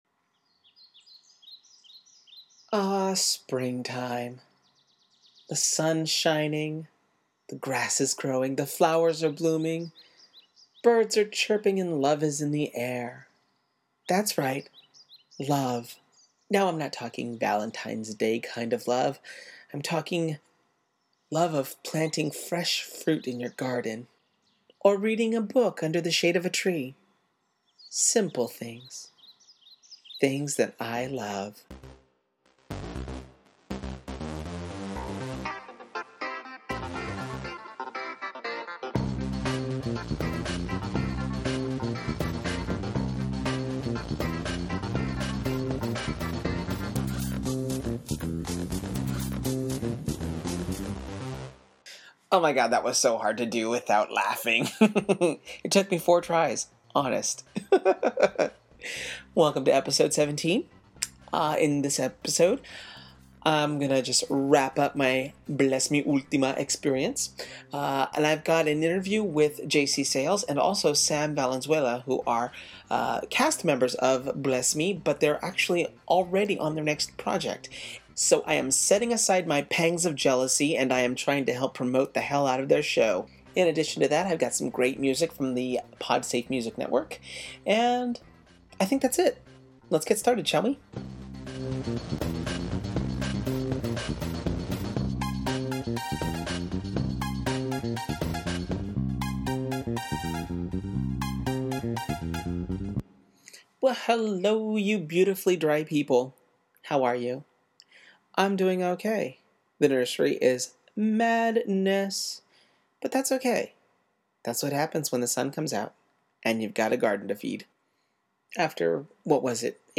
Episode 17! 2 (The Re-Do) (Click to play) For show notes, click HERE I guess I left one of the tracks on mute when I uploaded the podcast.